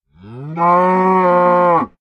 cow_say3.ogg